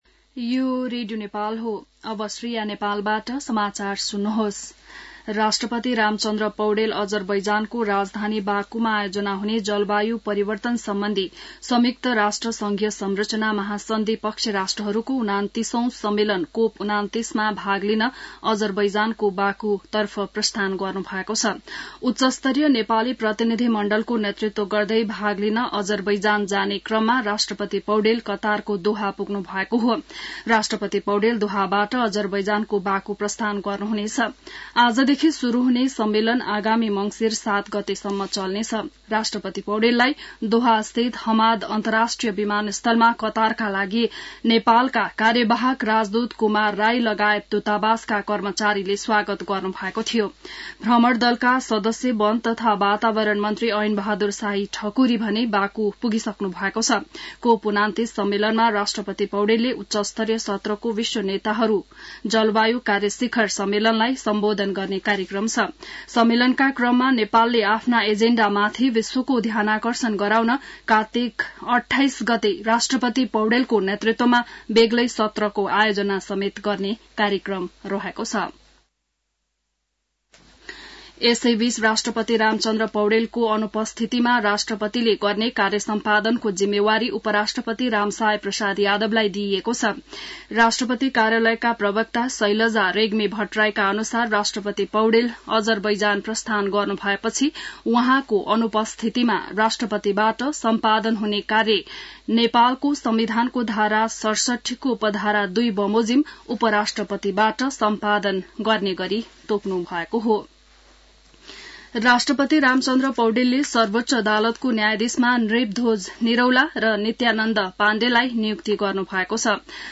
बिहान ६ बजेको नेपाली समाचार : २७ कार्तिक , २०८१